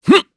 Siegfried-Vox_Attack1_kr_b.wav